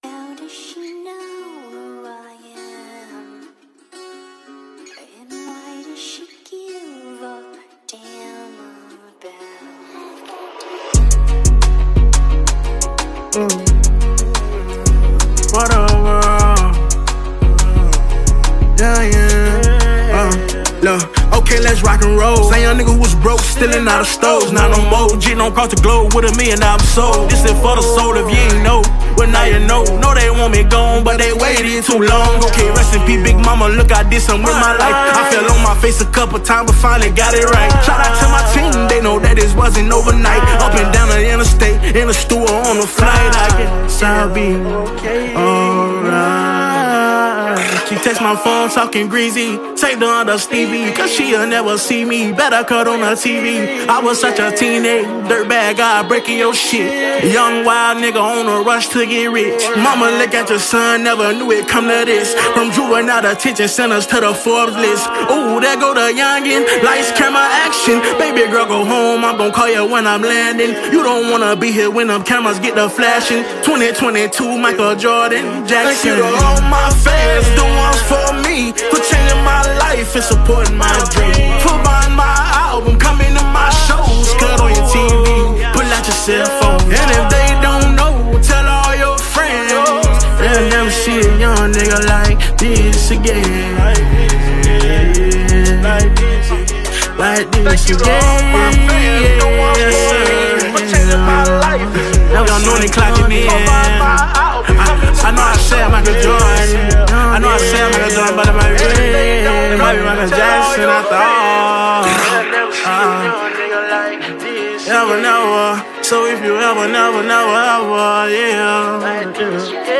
melodic sound and style
Hip-Hop